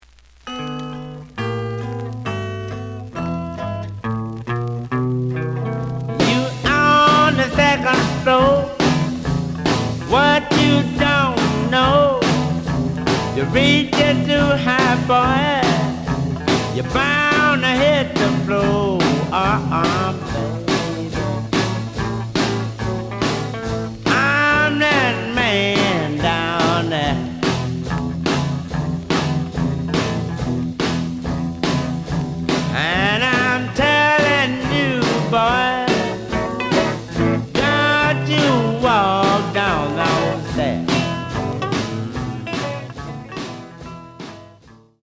The audio sample is recorded from the actual item.
●Genre: Blues
Slight noise on beginning of A side, but almost good.)